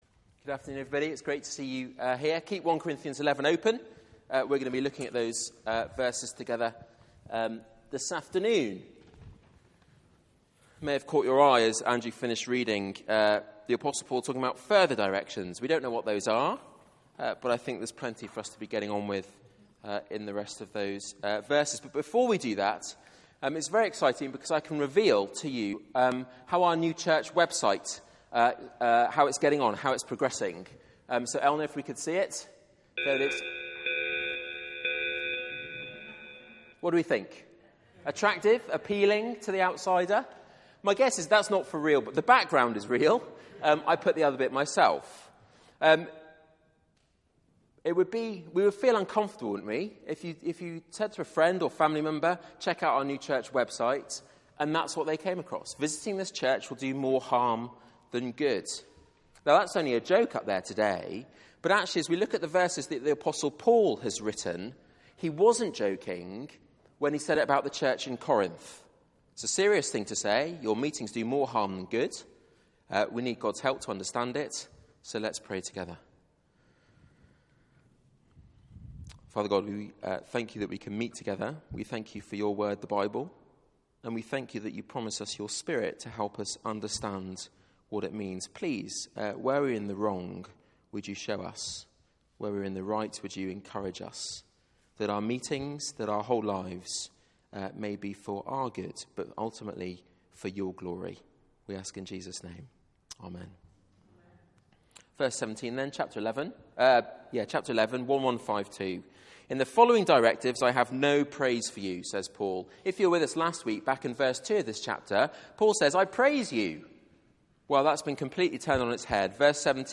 Media for 4pm Service on Sun 28th Jun 2015 16:00 Speaker
Sermon